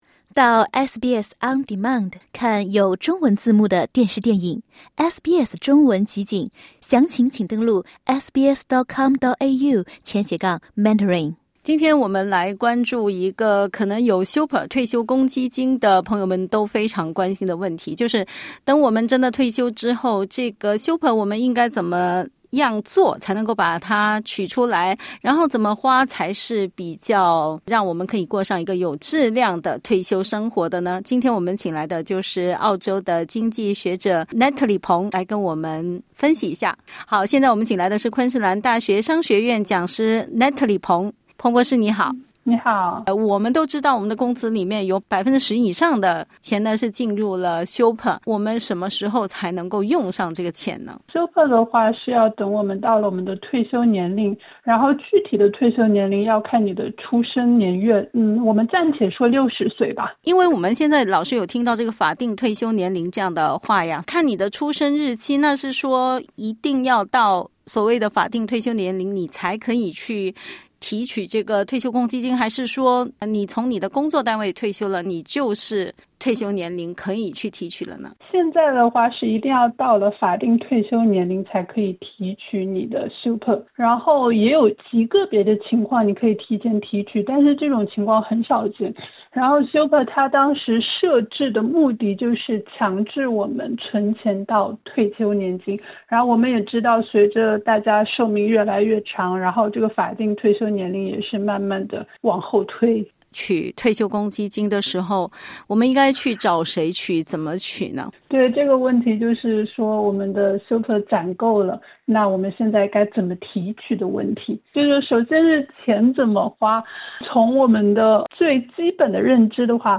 （点击音频收听详细采访） 格拉坦研究所的研究发现，80%的澳大利亚人表示养老金制度过于复杂，60%的人认为退休会给自己带来经济压力。